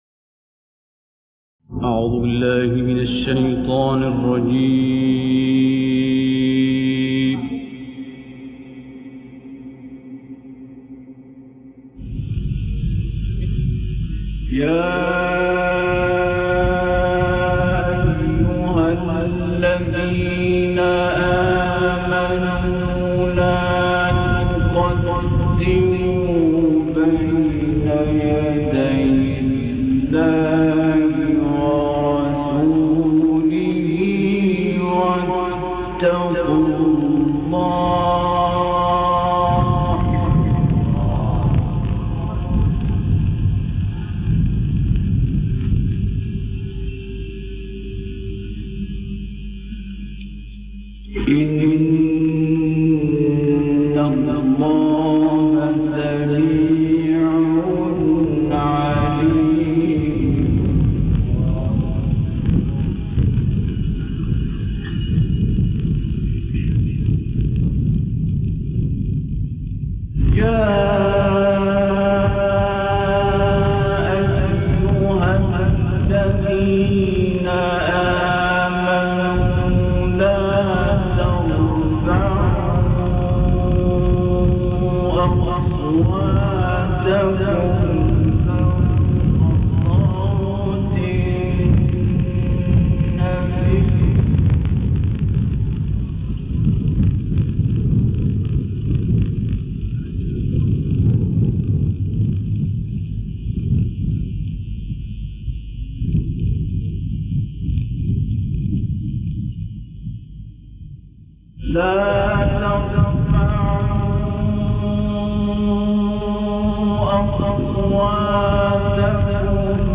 حفلات نادرة جدا للشيخ عبد الباسط عبد الصمد النادرة فى باكستان